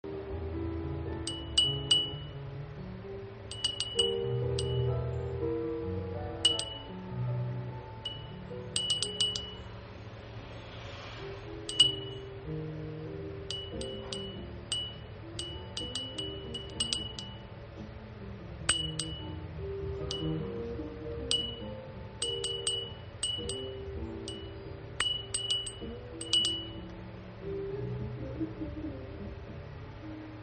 ガラス製卓上風鈴金魚